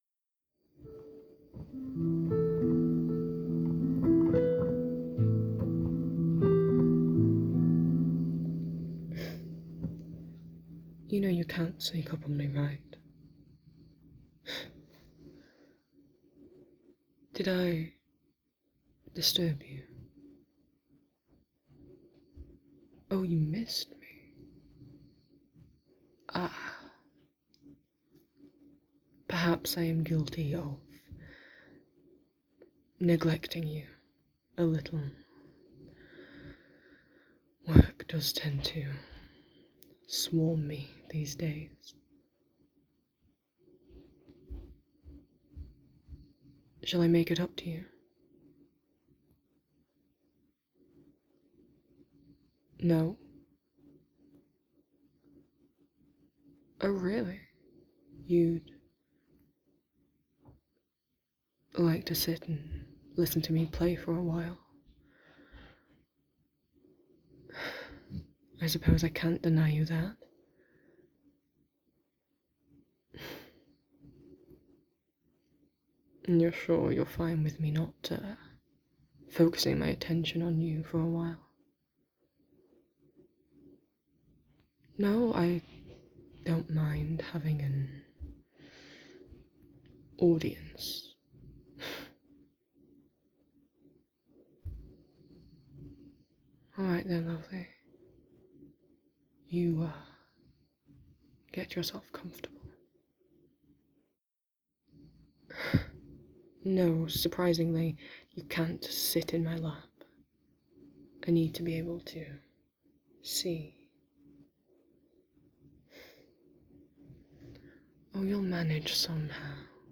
[M4A] [Romantic] [Piano] [Singing] [Humming] [Sleep aid]